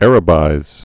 (ărə-bīz)